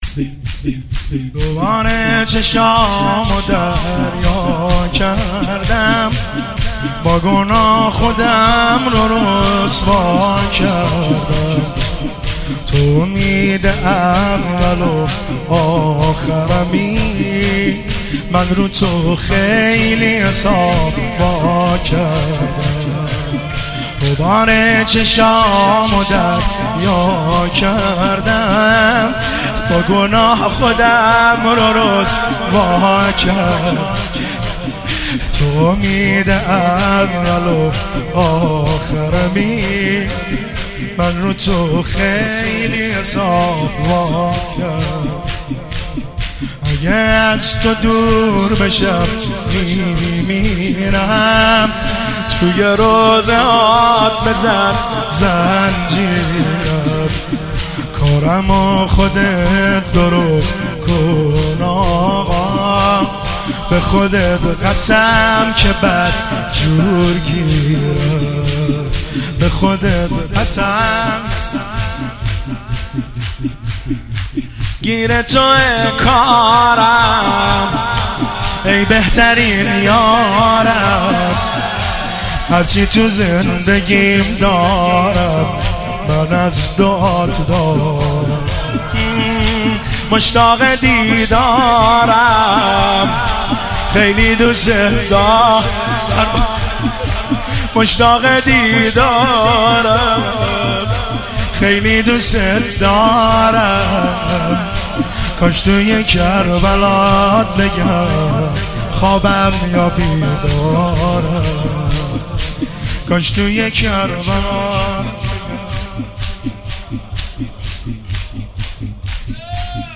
مداحی جدید